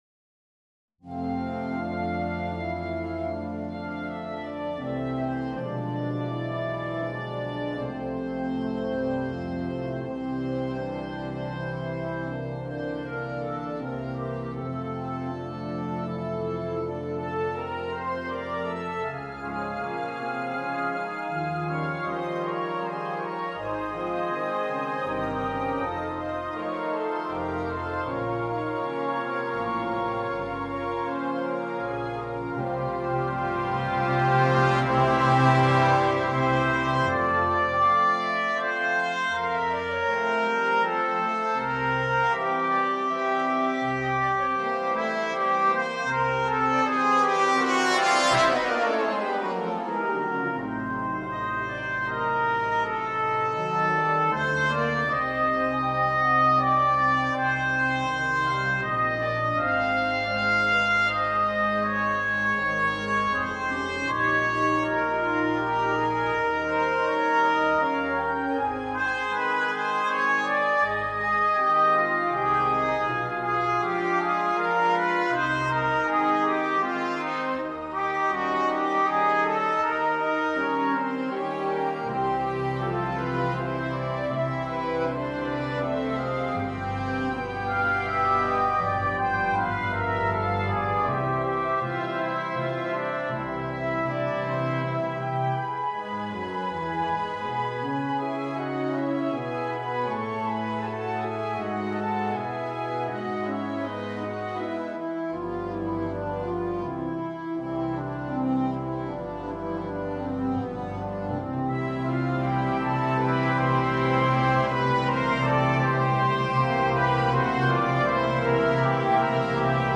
“concerto” per tromba